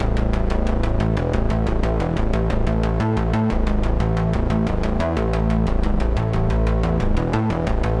Index of /musicradar/dystopian-drone-samples/Droney Arps/90bpm
DD_DroneyArp1_90-A.wav